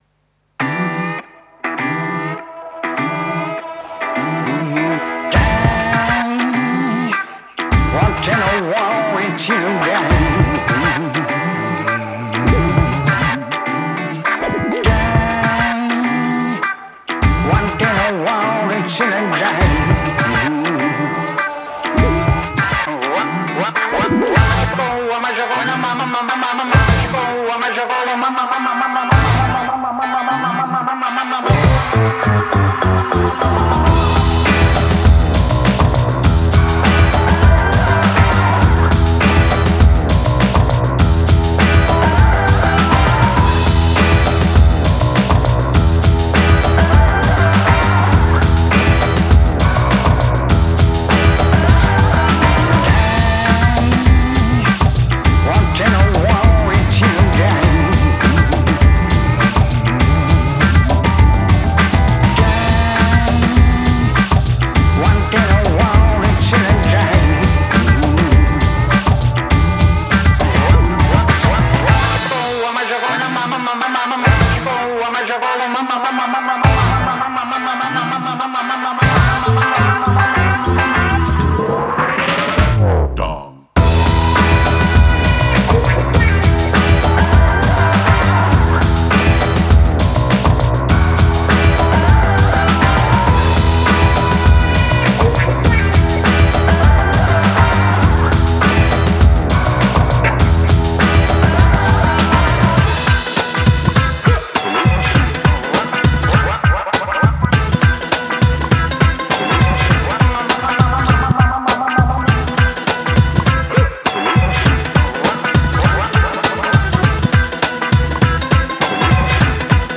Сделали новый трек в жанре Big Beat специально для кинА.))